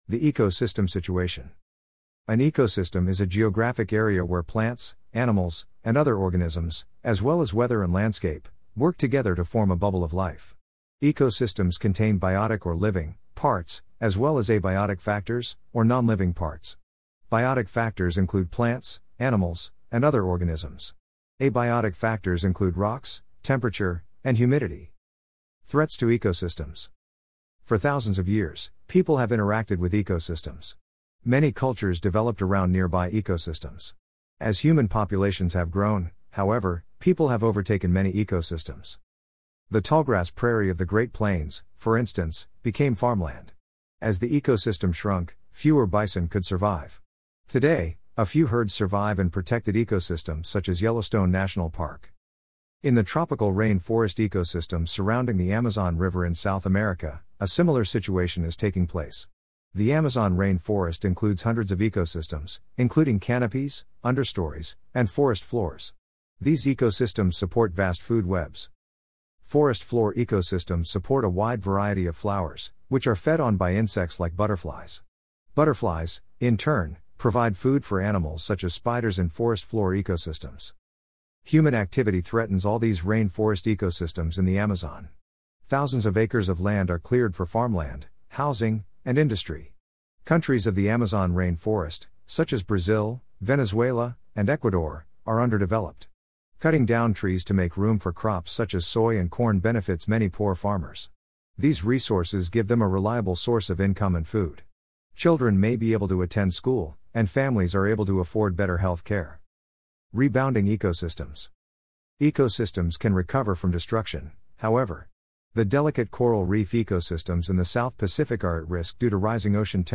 2. Text to Voice – Voice Over (ElevenLabs)